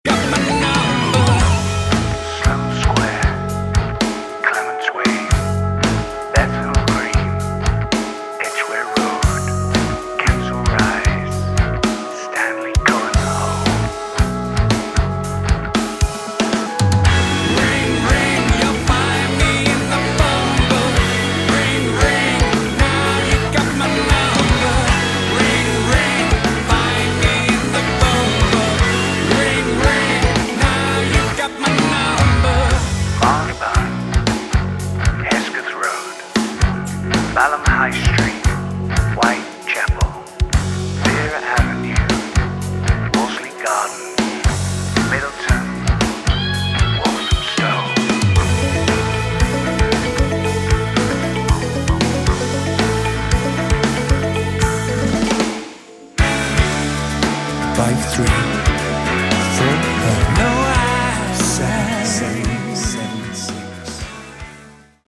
Category: Prog Rock
vocals
guitars, bass
drums
keyboards, Hammond organ, piano
Nice retro prog rock.